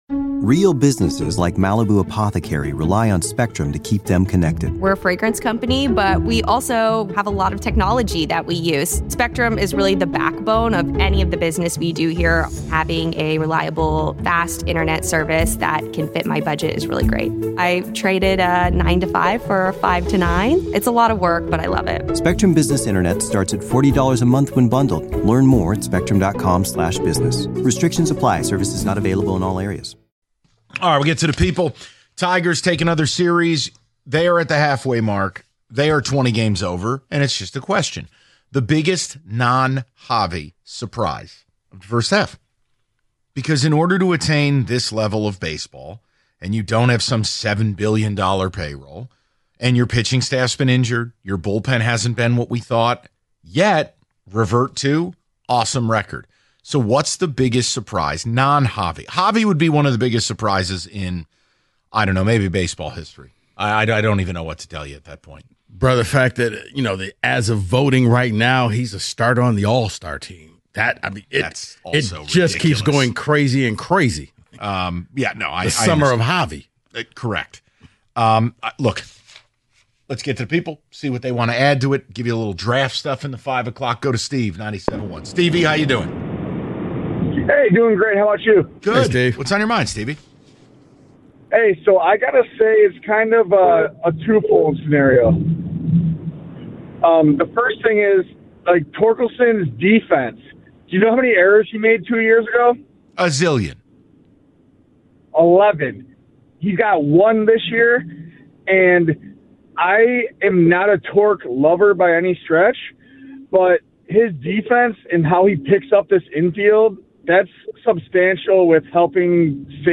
The People Give Their Biggest Tigers Surprise The Valenti Show Audacy Sports 3.8 • 1.1K Ratings 🗓 26 June 2025 ⏱ 9 minutes 🔗 Recording | iTunes | RSS 🧾 Download transcript Summary The guys hear from the people about their biggest surprises in the 2025 Tigers season.